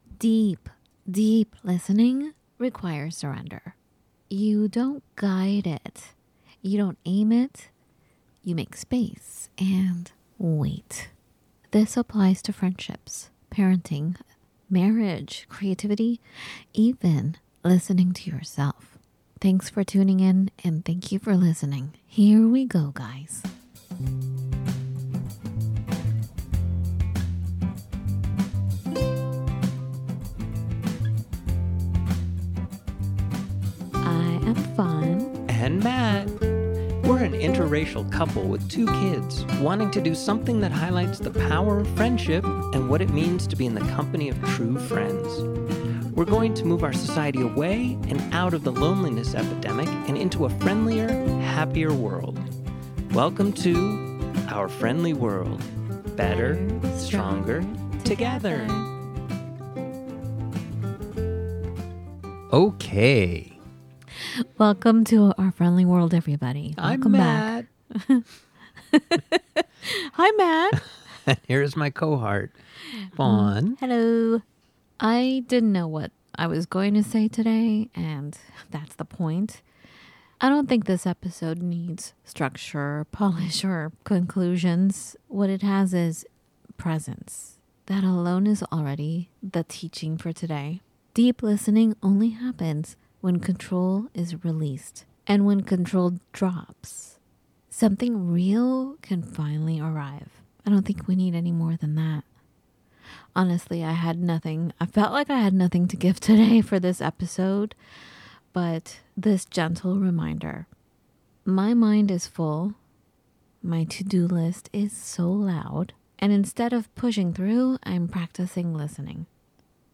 From Friedships and parenting to decision-making, fear, regret, and faith, this conversation unfolds without an agenda.